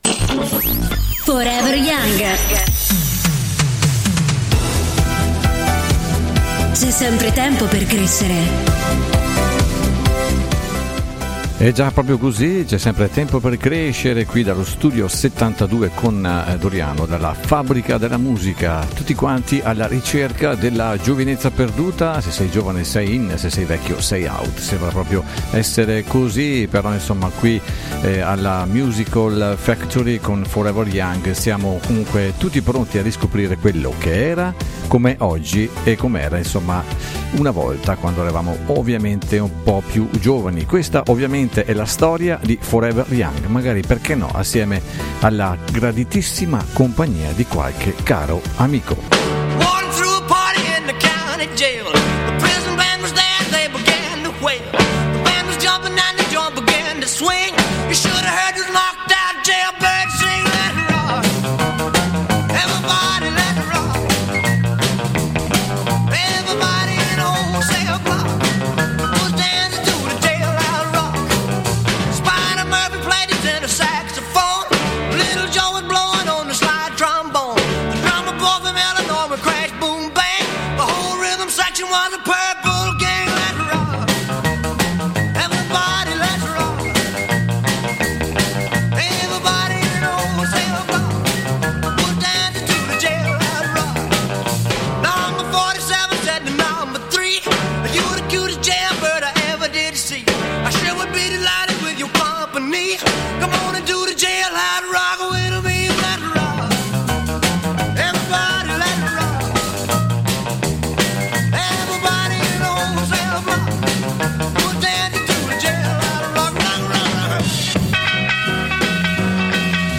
dallo studio 72 di Forever Young